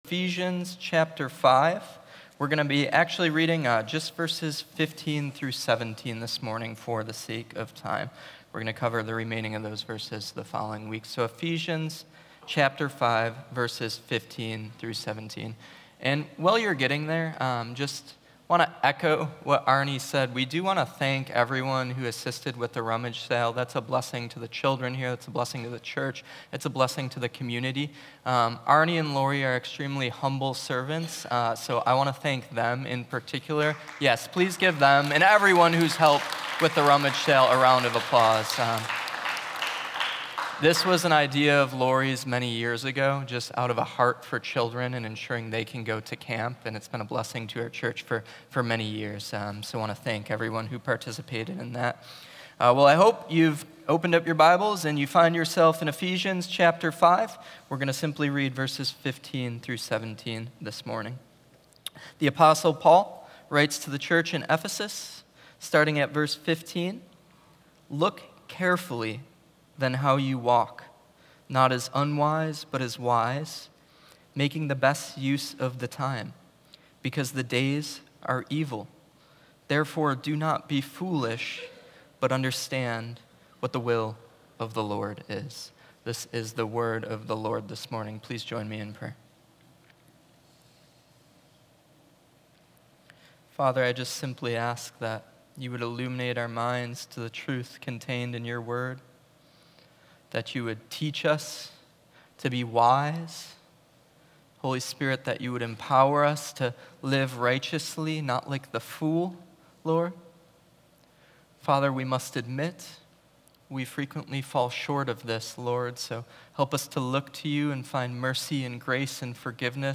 A message on how Christ equips every believer with spiritual gifts to strengthen and unify the Church.